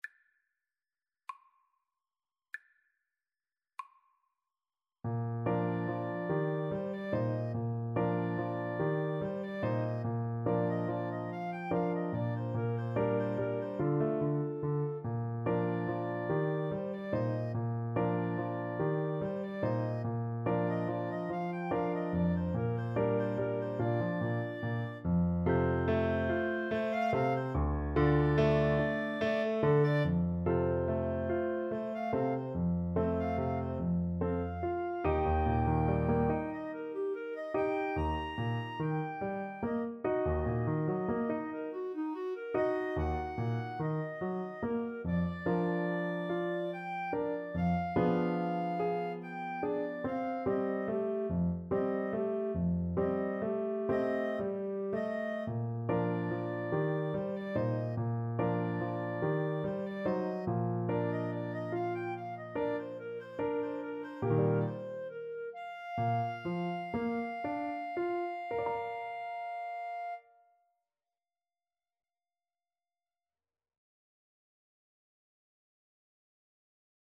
Play (or use space bar on your keyboard) Pause Music Playalong - Player 1 Accompaniment reset tempo print settings full screen
Bb major (Sounding Pitch) C major (Clarinet in Bb) (View more Bb major Music for Clarinet-Saxophone Duet )
Gently rocking = 144
6/8 (View more 6/8 Music)